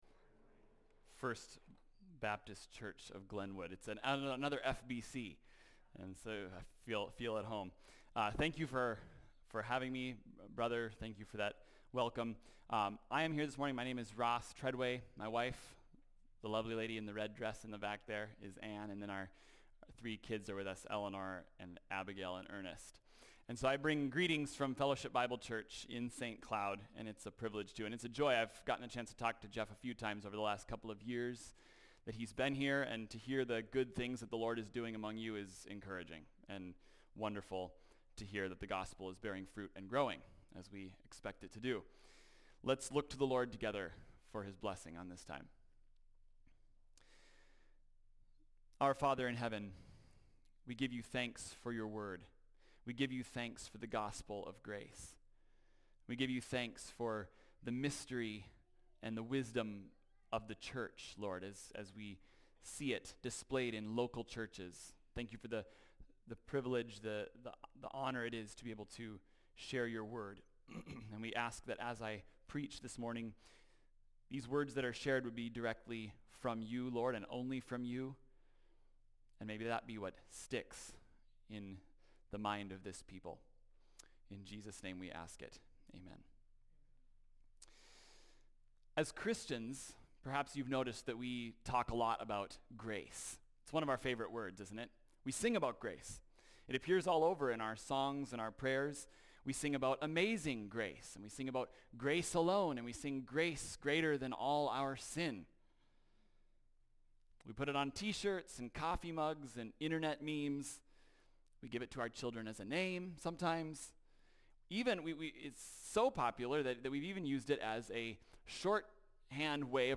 fbc_sermon_072025.mp3